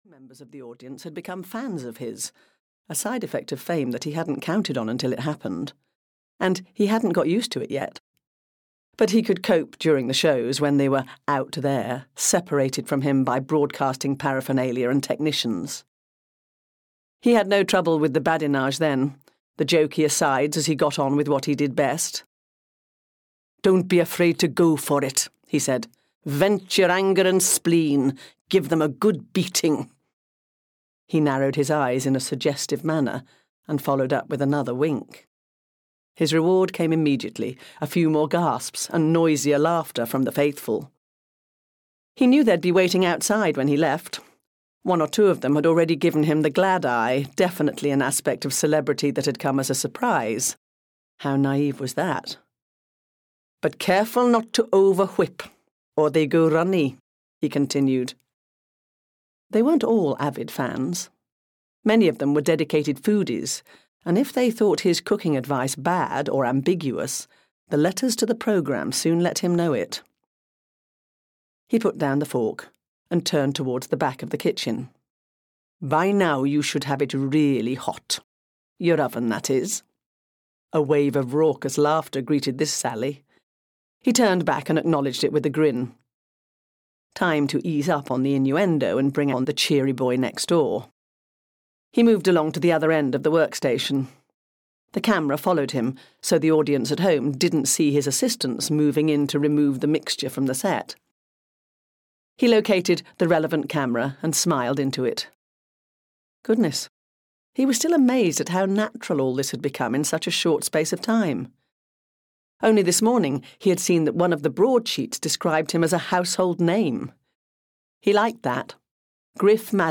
Season to Kill (EN) audiokniha
Ukázka z knihy